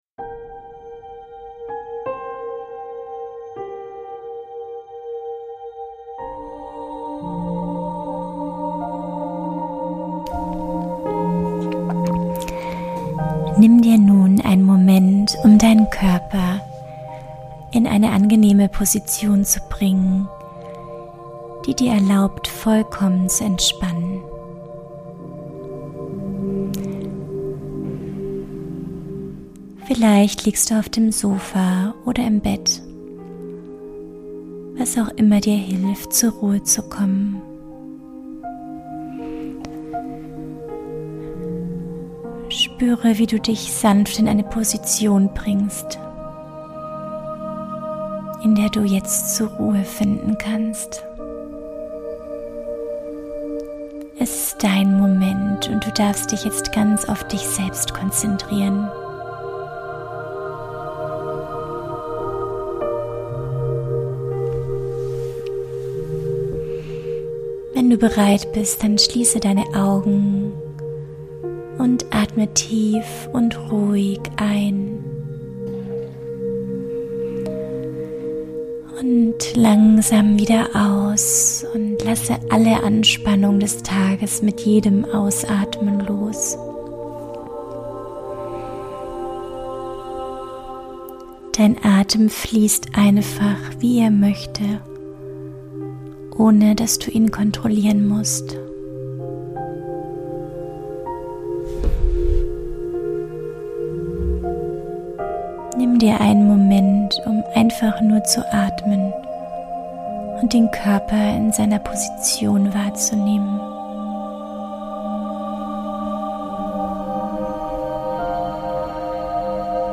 83 - Einschlafmeditation für Eltern ~ Einfach Familie leben Podcast